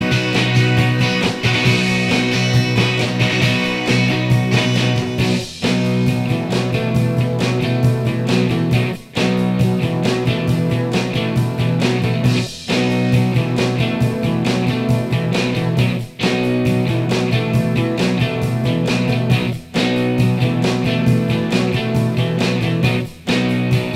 Minus Bass Guitar Rock 3:49 Buy £1.50